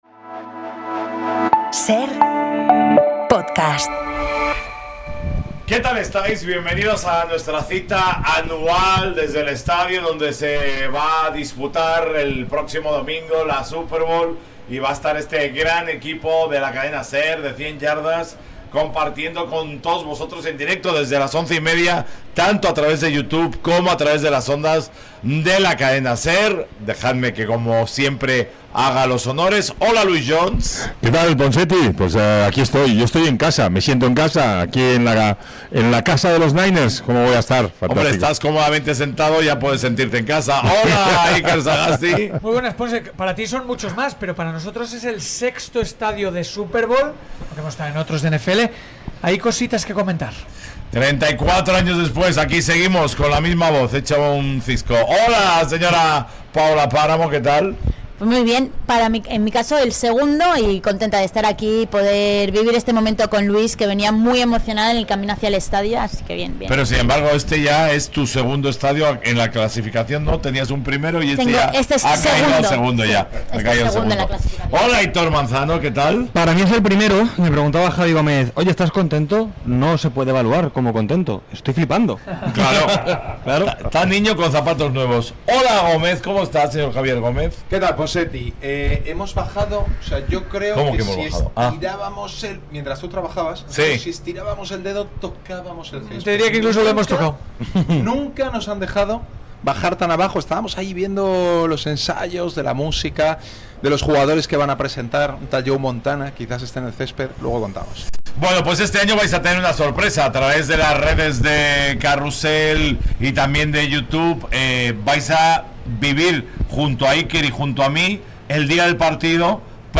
Nos vamos directamente al Levis Stadium para analizarte directamente en el escenario de la Super Bowl el partido de los partidos de fútbol americano